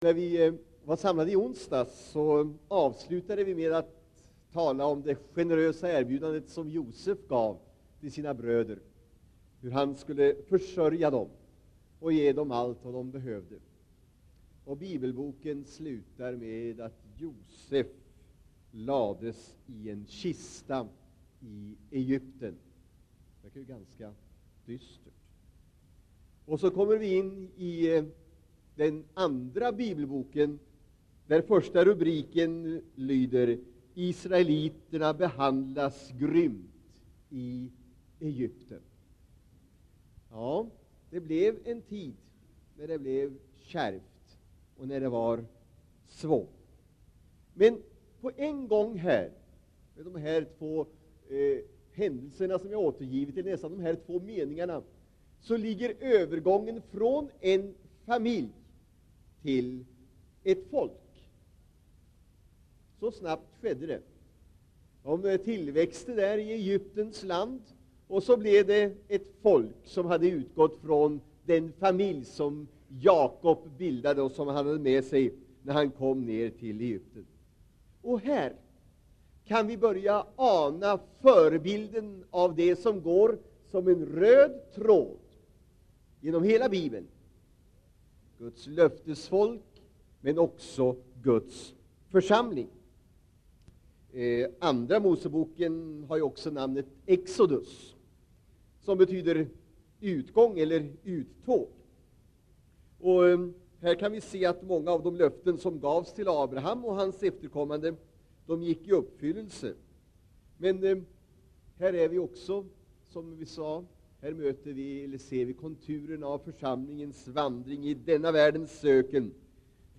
Inspelad i Citykyrkan, Stockholm 1984-02-22.
Predikan får kopieras och spridas men inte läggas ut på nätet, redigeras eller säljas.